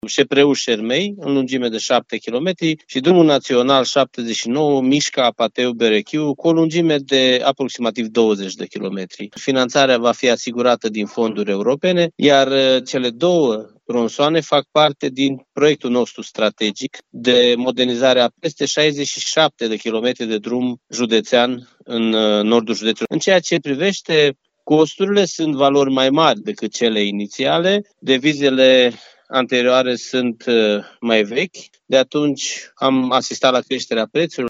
În total, cele două lucrări depășesc 100 de milioane de lei, spune președintele Consiliul Județean Arad, Iustin Cionca.